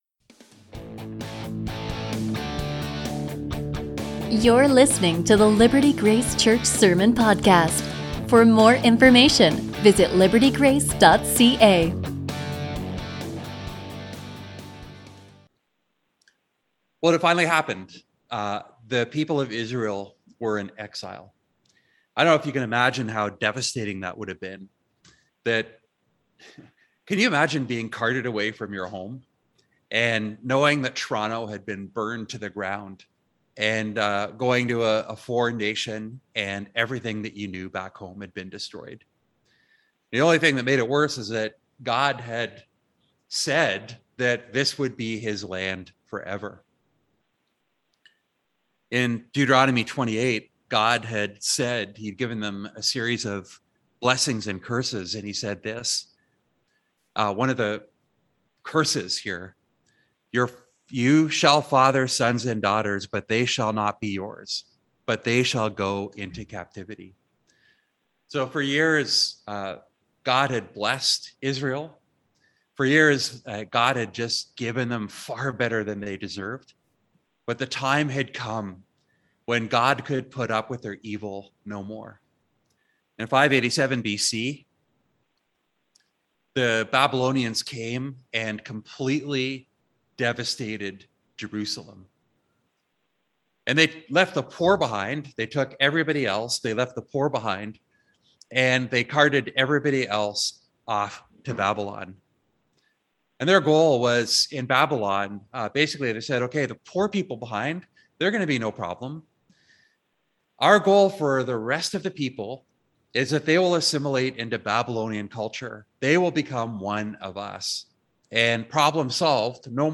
A sermon from Jeremiah 29:4-14